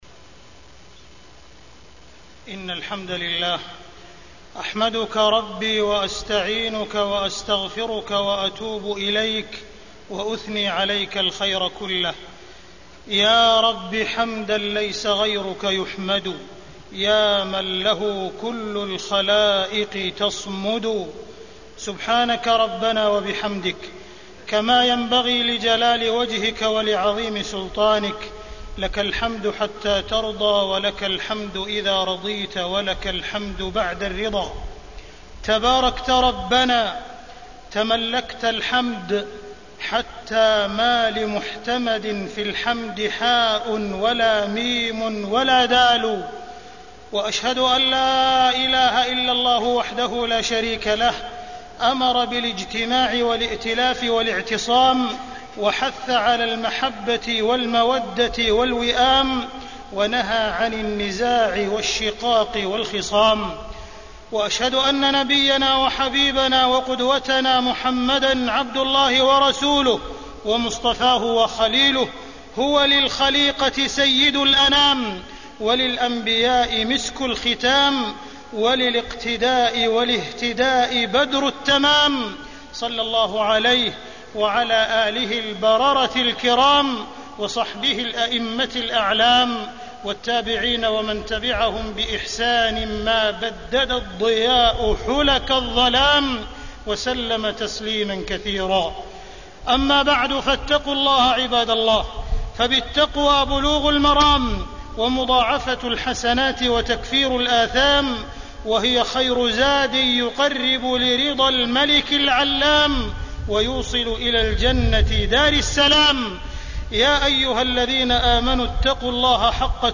تاريخ النشر ٢٥ ربيع الأول ١٤٢٨ هـ المكان: المسجد الحرام الشيخ: معالي الشيخ أ.د. عبدالرحمن بن عبدالعزيز السديس معالي الشيخ أ.د. عبدالرحمن بن عبدالعزيز السديس الوحدة والإئتلاف